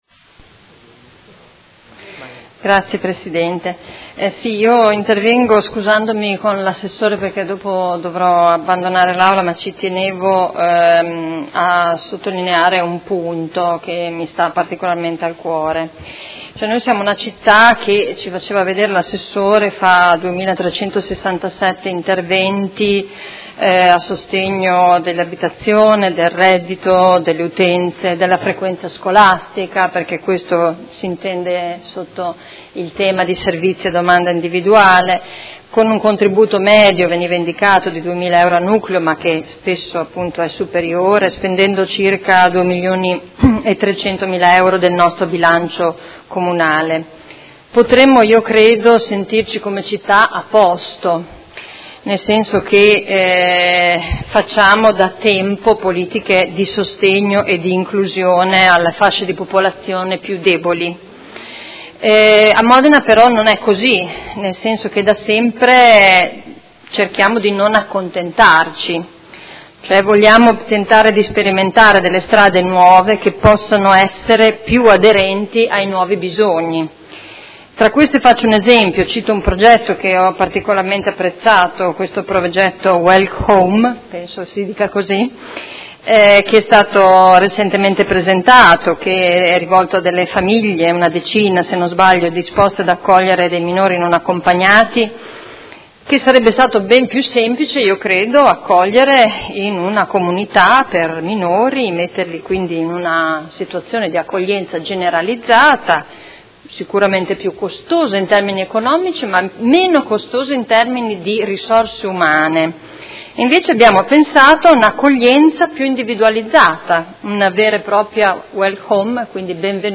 Seduta del 20/10/2016. Dibattito su proposta di deliberazione e ordini del giorno sul tema della solidarietà civica